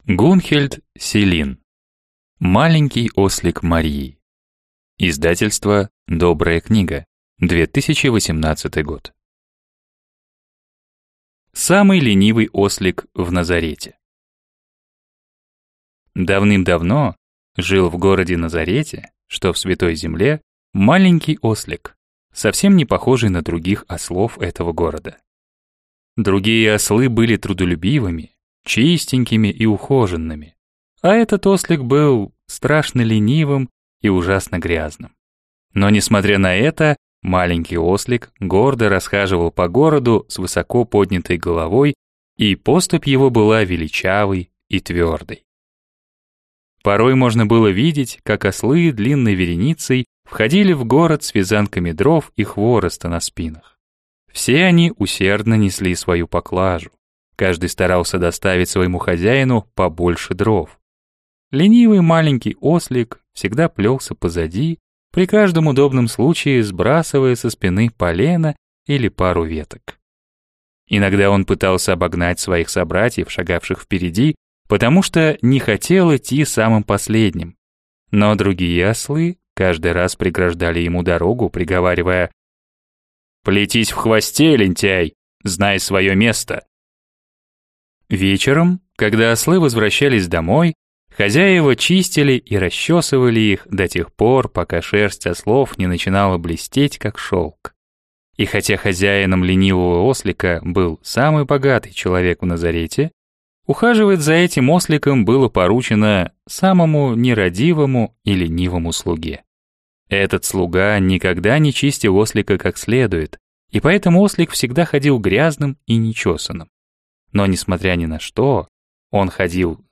Аудиокнига Маленький ослик Марии | Библиотека аудиокниг